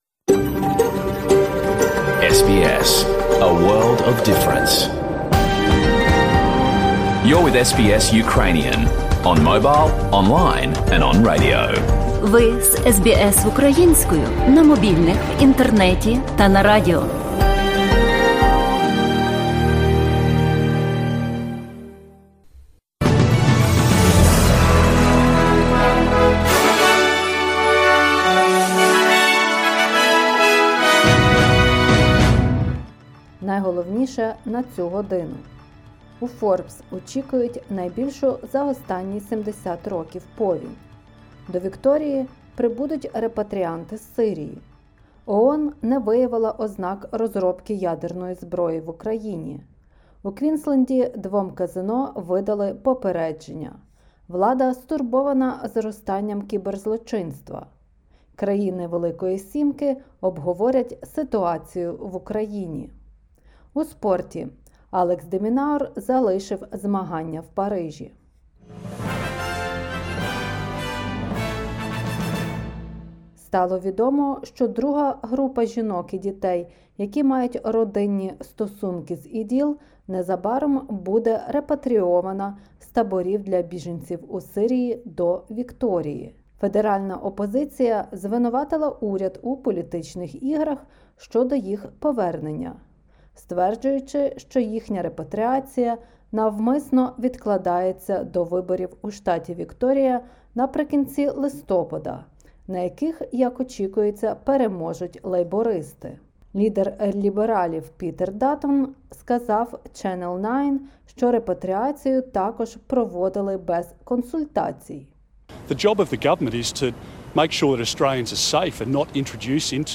SBS news in Ukrainian – 04/11/2022